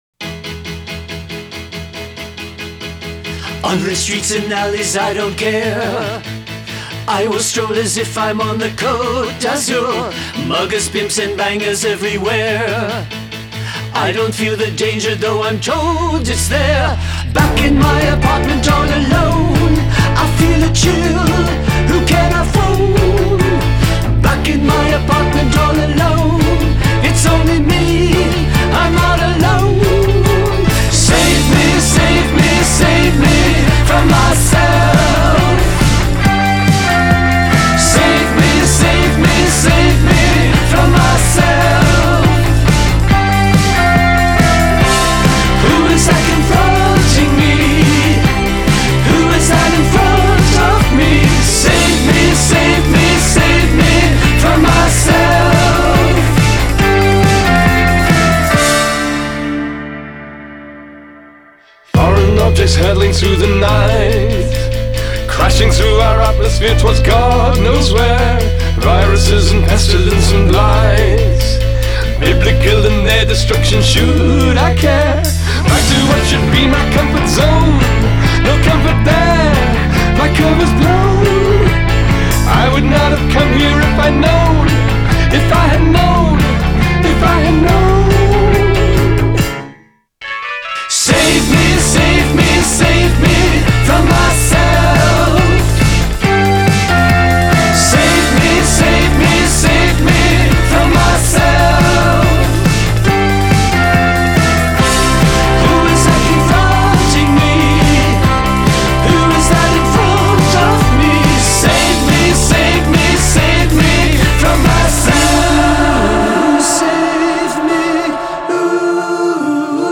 Genre: Indie Rock